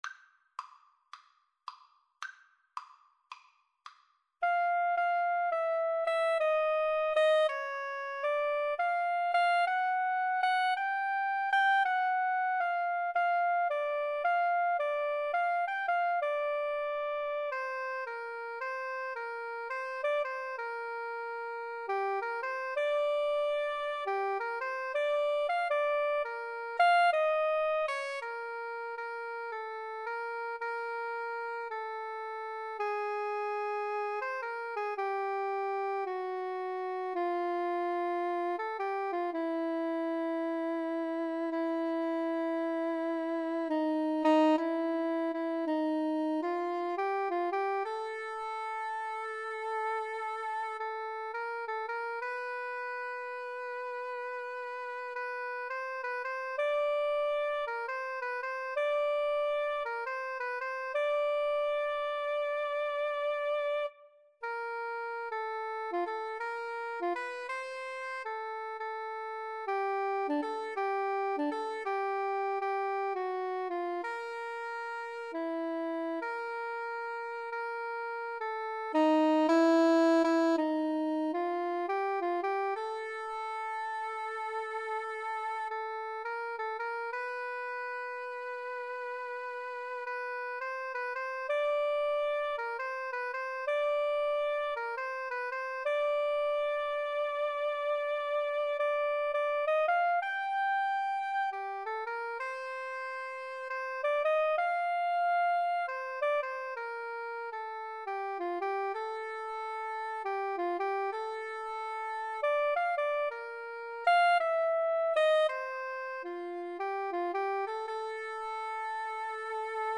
~ = 110 Moderate swing